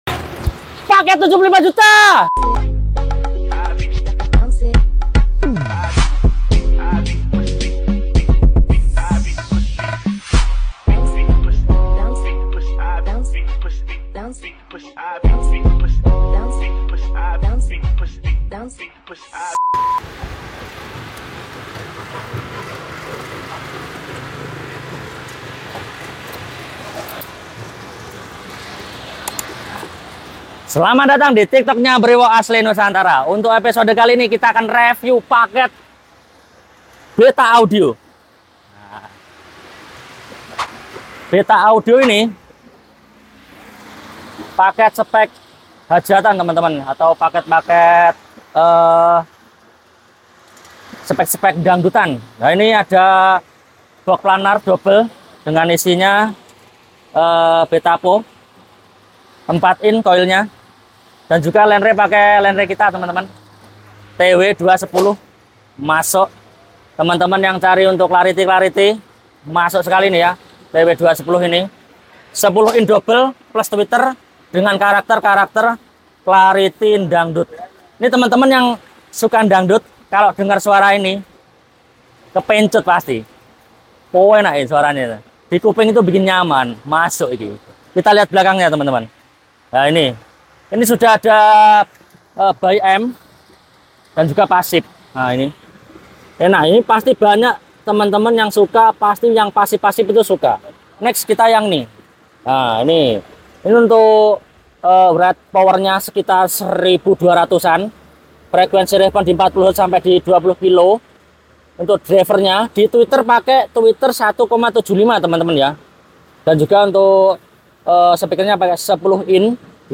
MANTAPP CEK SOUND DANGDUTNYA !!